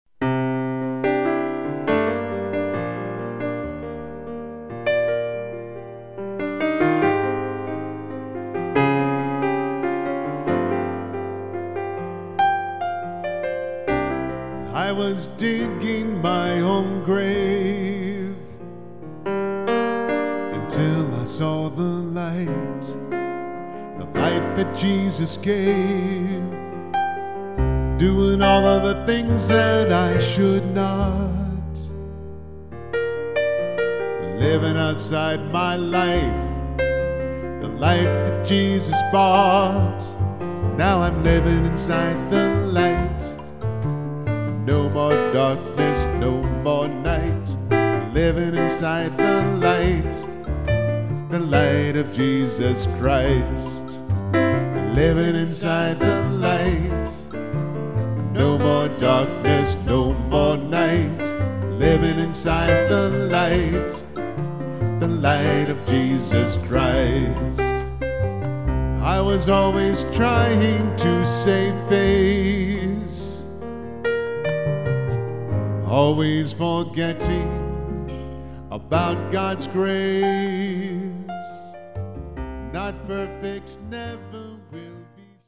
Contemporary Christian music
Keyboard/Vocals